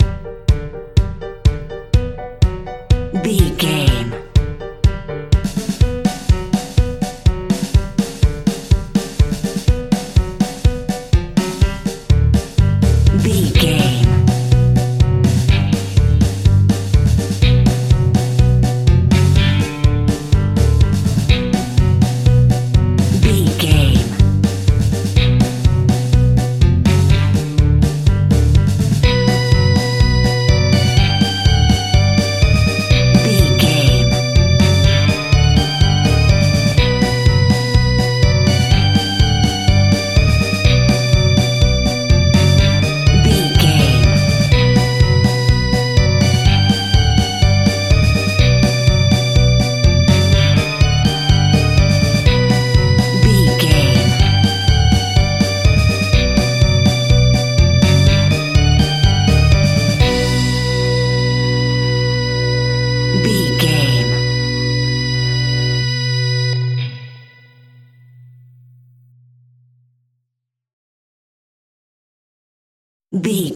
Diminished
scary
ominous
dark
haunting
eerie
electric organ
piano
bass guitar
drums
horror music
Horror Pads
Horror Synths